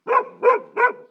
Ladridos de un perro grande